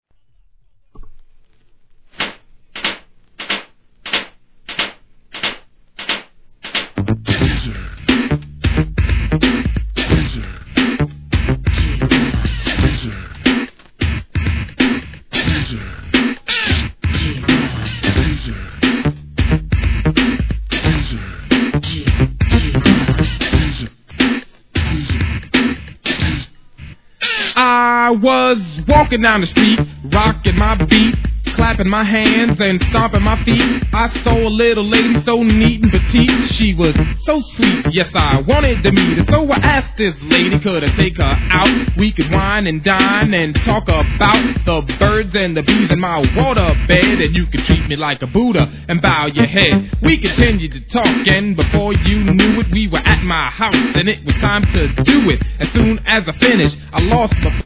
HIP HOP/R&B
'80s OLD SCHOOL!!